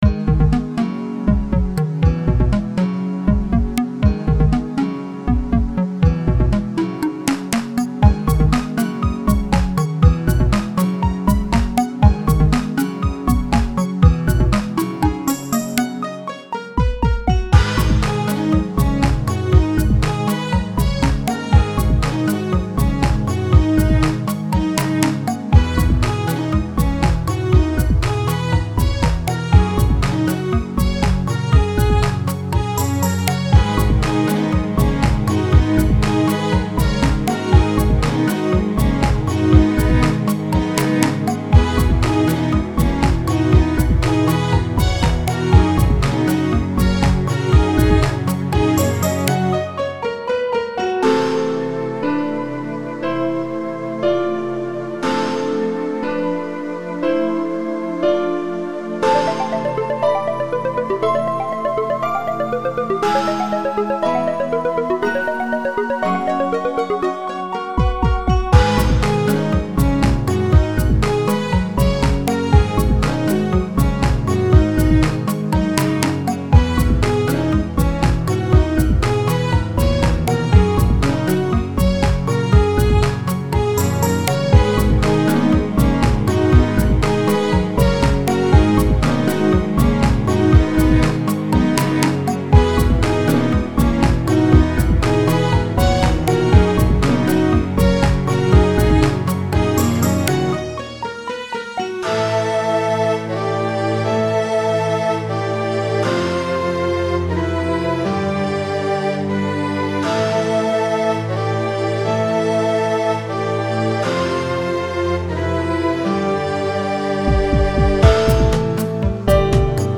标题画面的主题曲。
为了强调现实在变动、异变在迫近的感觉而使用了强劲的techno节拍，
不过也加入了另一些旋律与乐器，来暗示故事更加感性的一面。